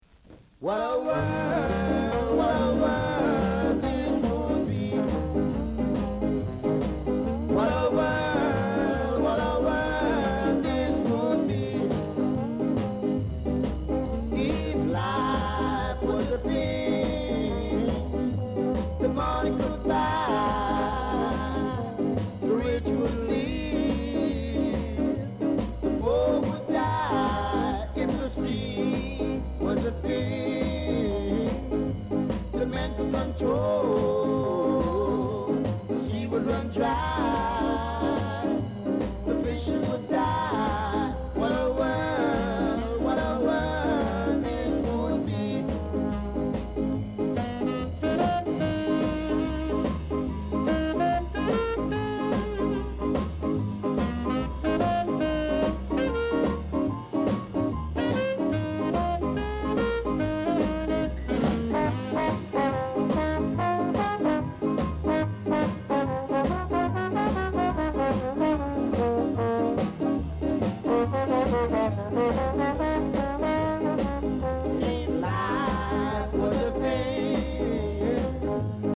Ska Vocal Duo
Side1 sample
Rare! great ska vocal w-sider!